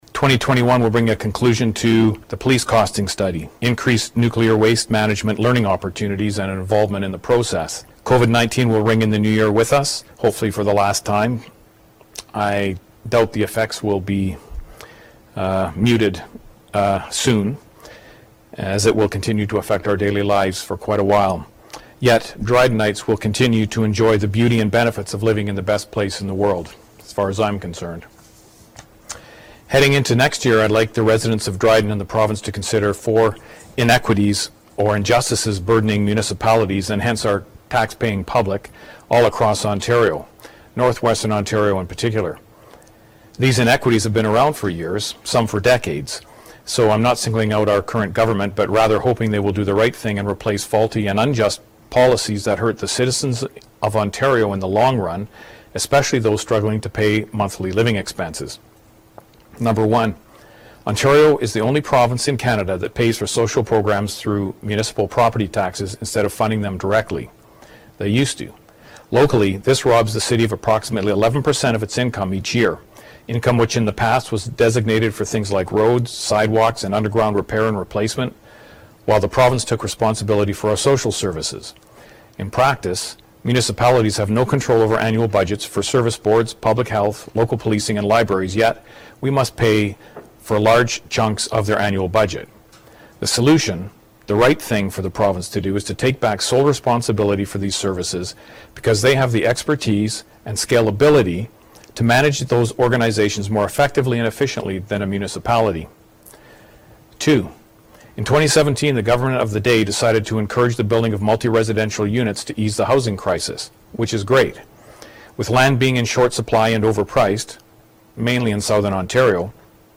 Mayor Greg Wilson talks about one of the highlights for this year.